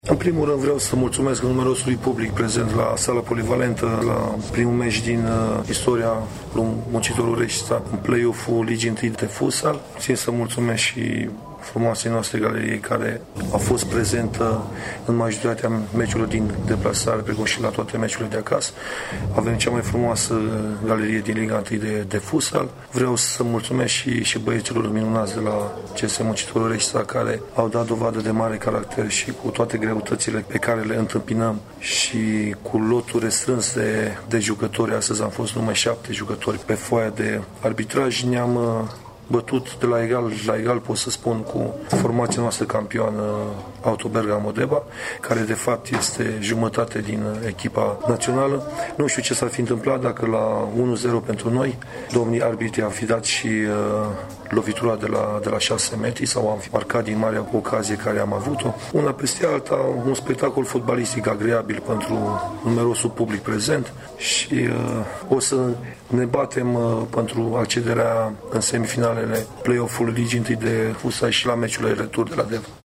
declaraţia